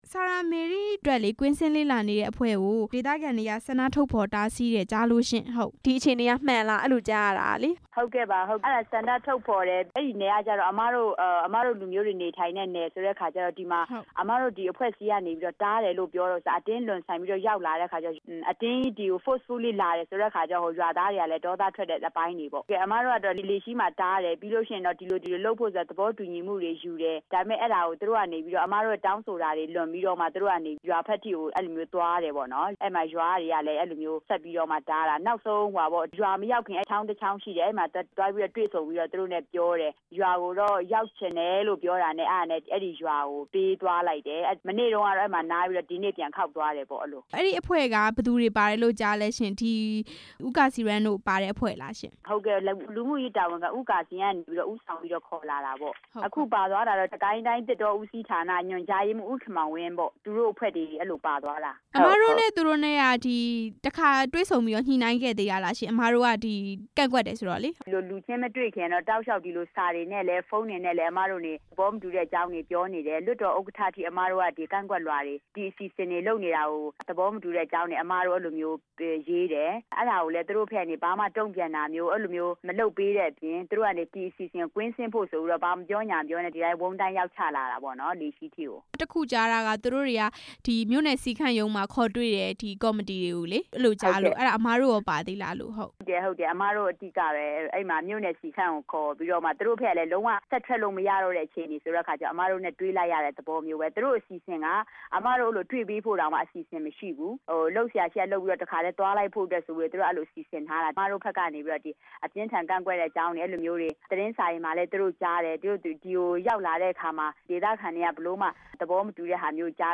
စာရာမေတိတောင် သွားတဲ့အဖွဲ့ကို ဒေသခံတွေ ကန့်ကွက်တဲ့အကြောင်း မေးမြန်းချက်